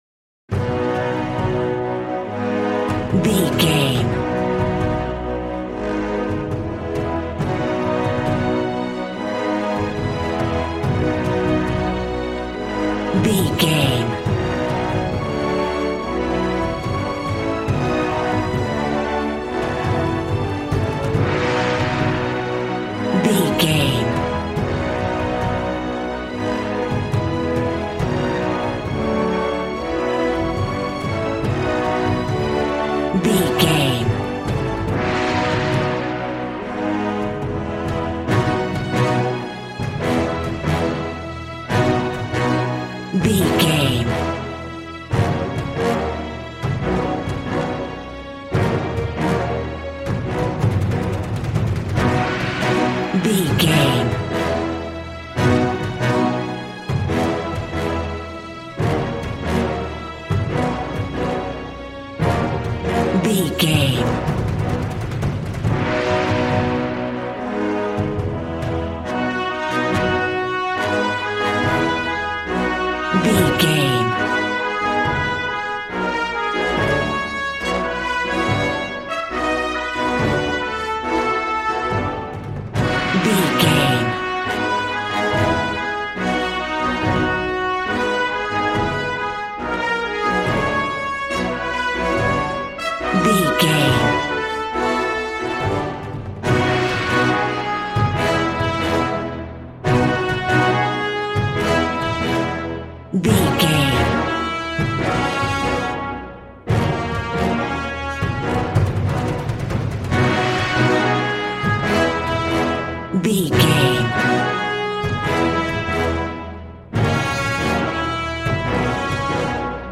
Ionian/Major
groovy
drums
bass guitar
electric guitar